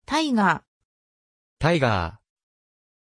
Aussprache von Tiger
pronunciation-tiger-ja.mp3